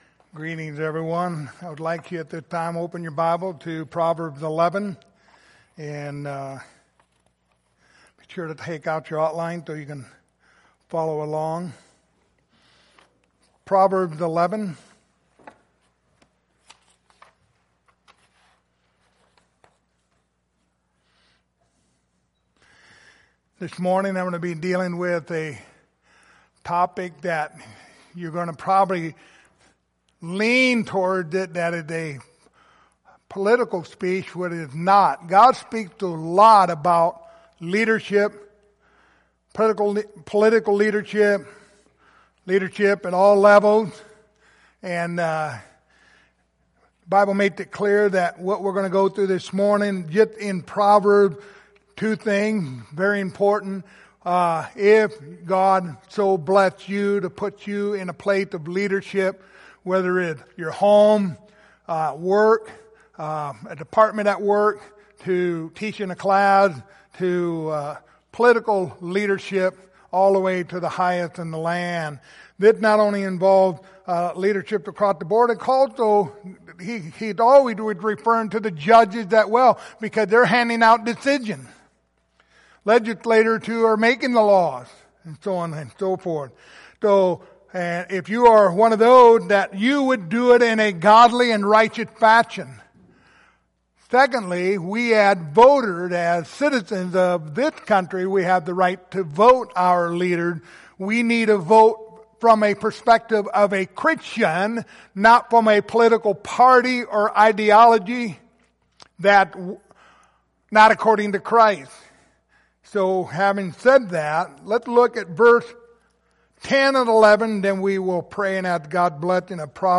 Series: The Book of Proverbs Service Type: Sunday Morning